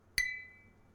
Glass Sound
Ding Glass Ring Ting sound effect free sound royalty free Sound Effects